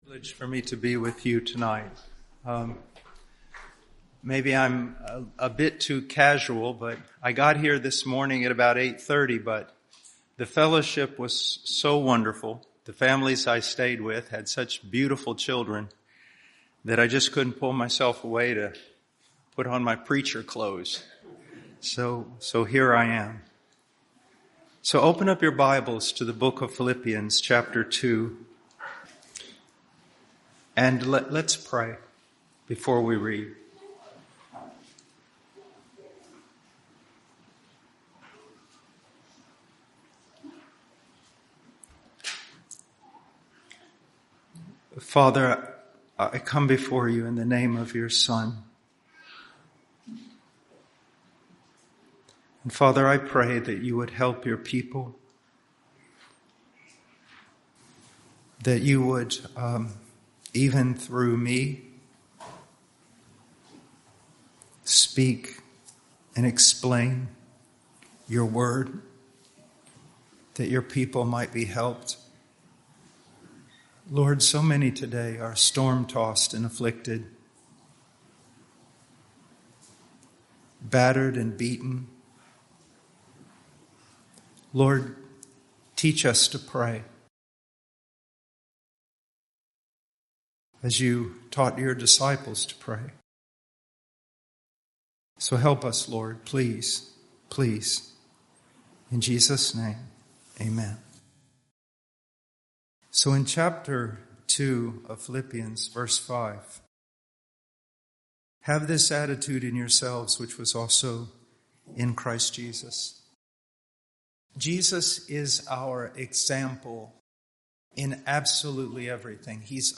Full Sermons There is something to live for!